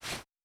Ball Throw Fast.wav